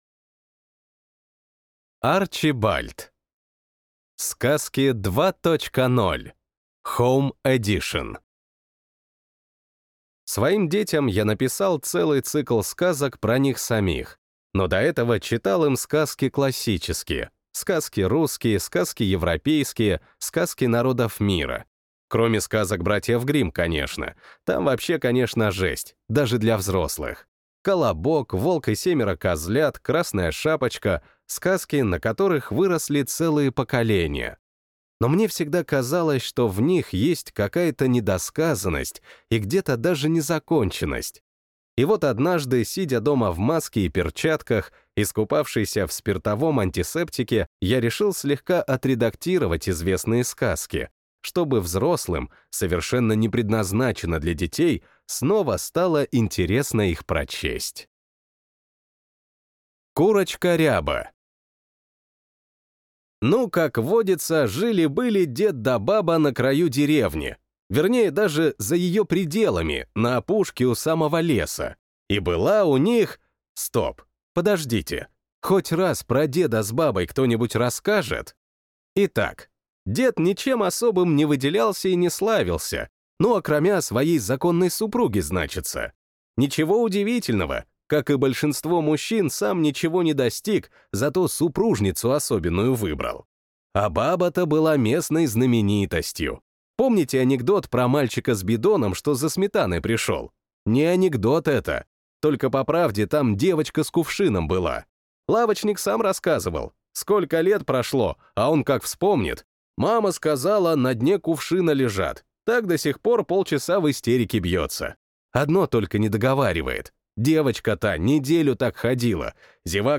Аудиокнига Сказки 2.0 (HOME EDITION) | Библиотека аудиокниг